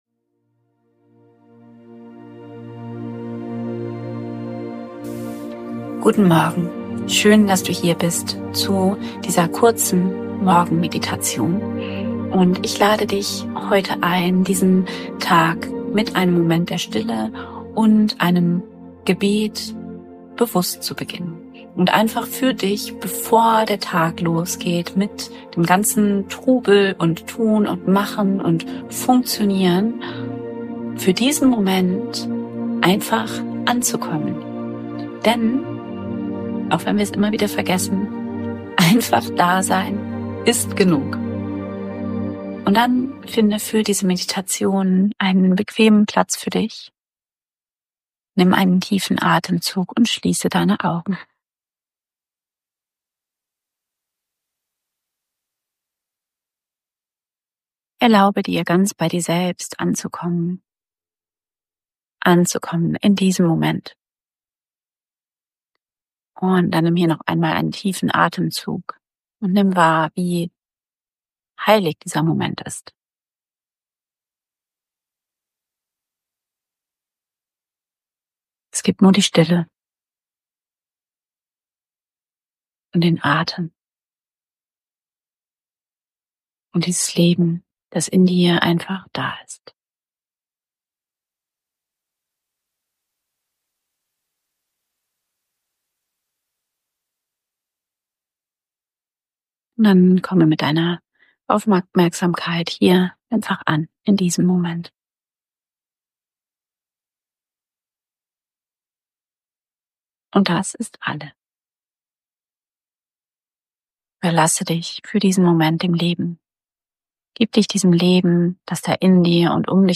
Diese kurze Morgenmeditation lädt dich ein, den Tag bewusst in Stille und Gebet zu beginnen.